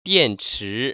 [diànchí] 띠앤치  ▶